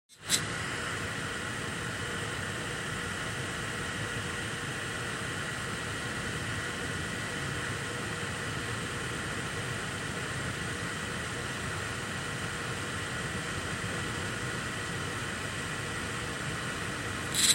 Запись с диктофона, расположенного в паре сантиметров от системы охлаждения Manli GeForce GTX 1060 Gallardo, при неработающих вентиляторах.